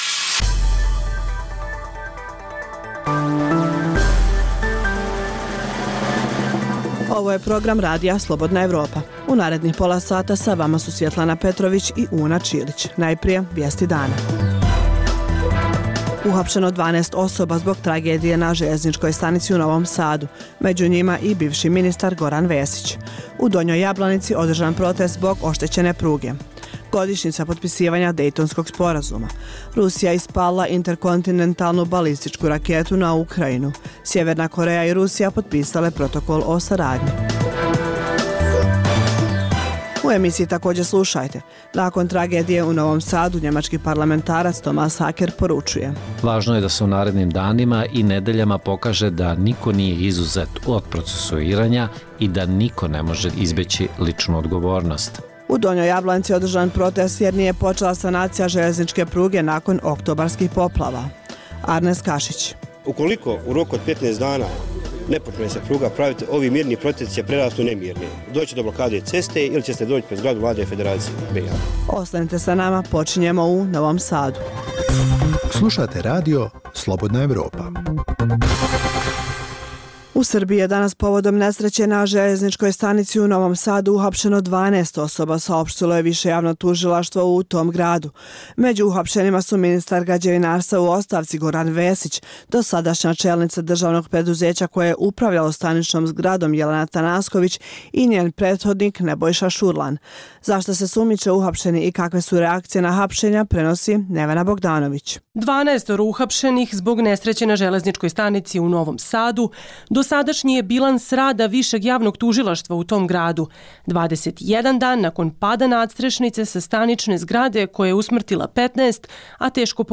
Preostalih pola sata emisije sadrži analitičke priloge iz svih zemalja regiona i iz svih oblasti, od politike i ekonomije, do kulture i sporta. Reportaže iz svakodnevnog života ljudi su svakodnevno takođe sastavni dio “Dokumenata dana”.